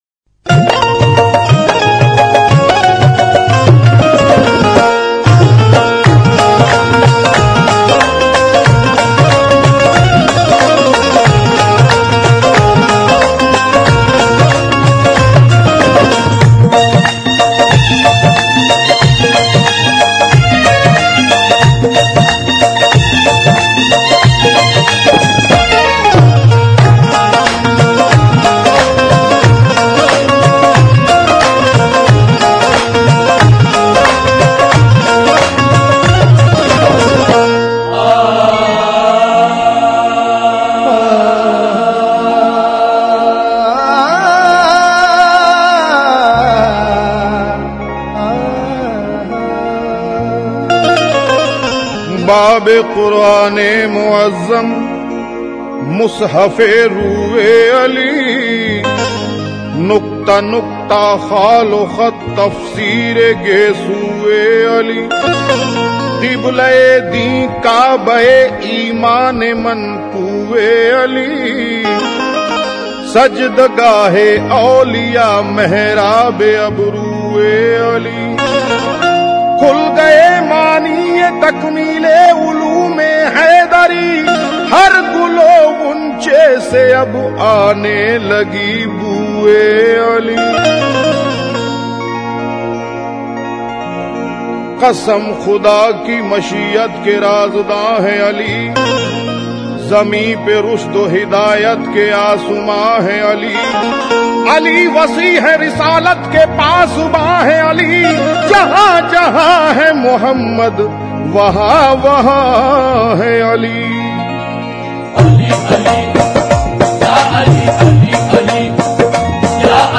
Islamic Qawwalies And Naats > Dargahon Ki Qawwaliyan